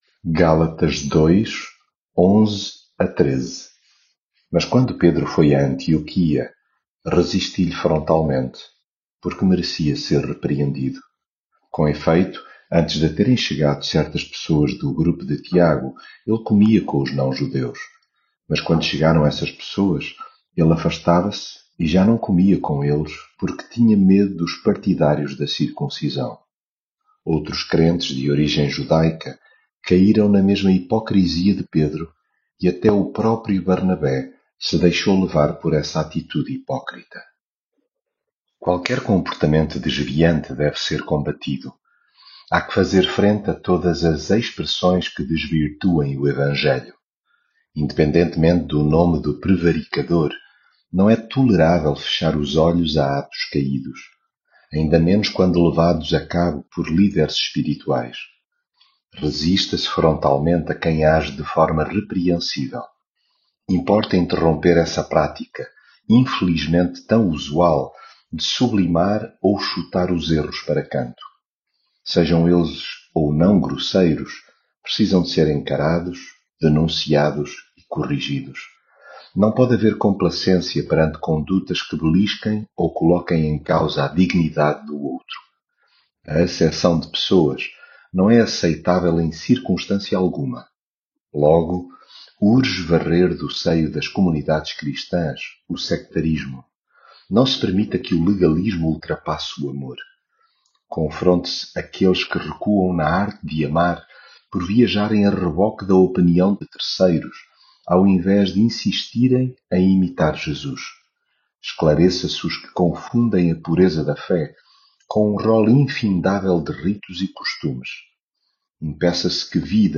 devocional gálatas leitura bíblica Mas quando Pedro foi a Antioquia, resisti-lhe frontalmente, porque merecia ser repreendido.